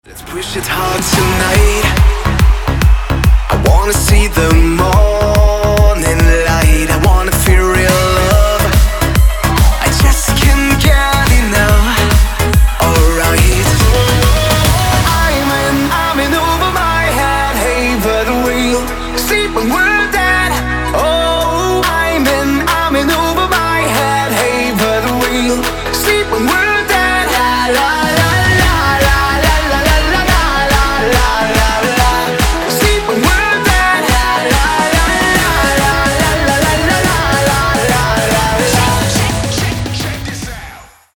• Качество: 192, Stereo
мужской вокал
dance
Electronic
club
клубные